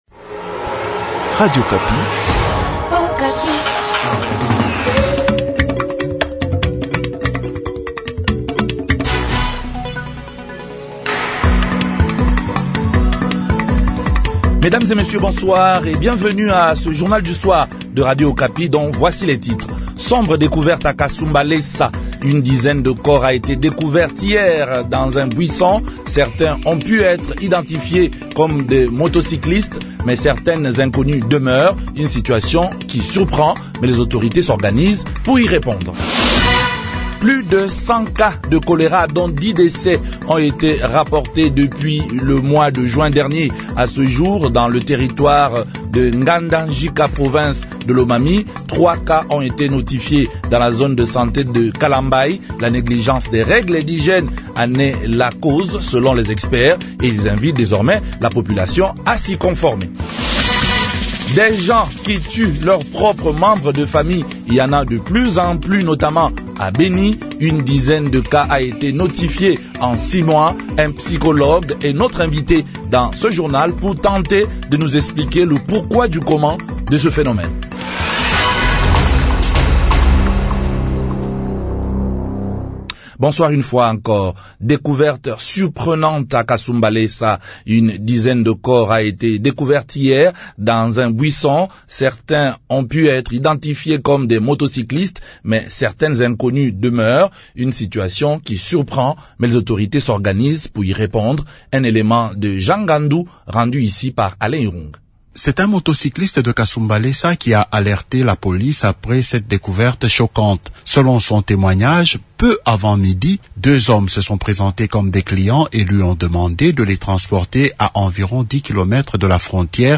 Un psychologue est notre invite dans ce journal pour tenter de nous expliquer le pourquoi du comment de ce phénomène.